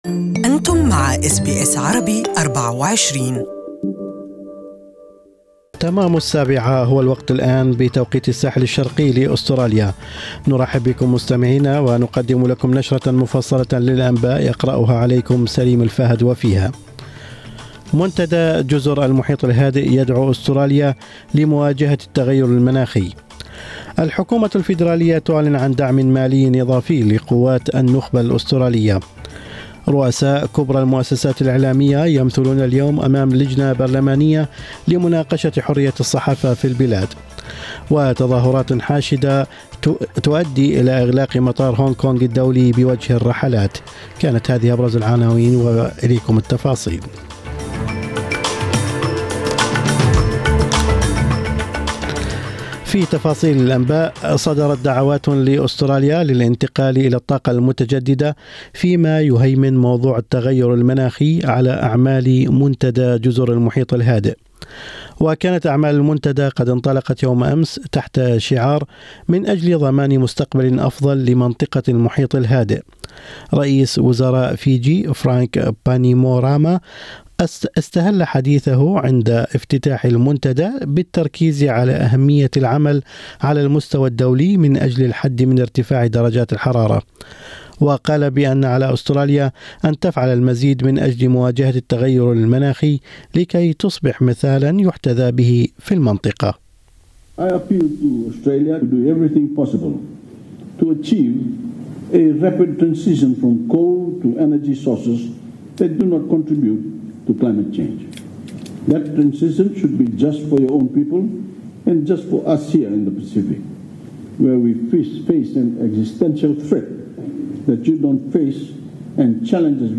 أخبار الصباح: حرية الصحافة وحماية الأمن القومي تحت نظر لجنة برلمانية مشتركة